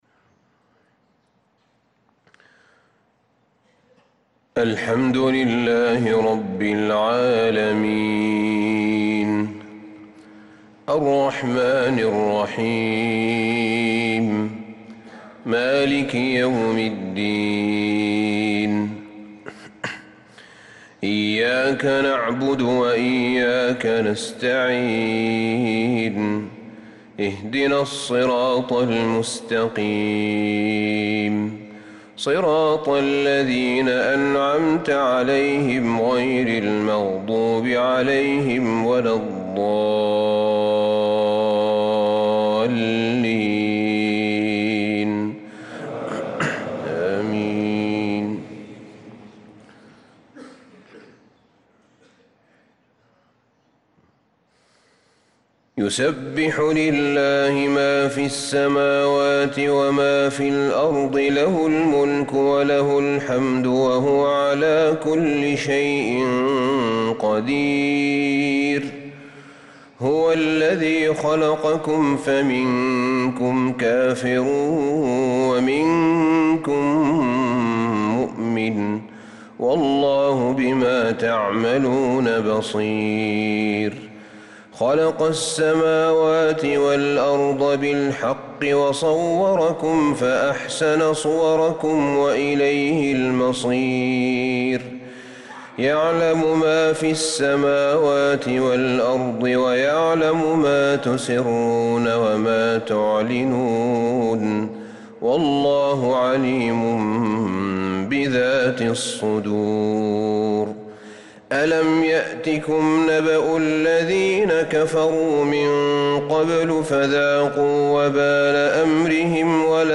صلاة الفجر للقارئ أحمد بن طالب حميد 18 محرم 1446 هـ
تِلَاوَات الْحَرَمَيْن .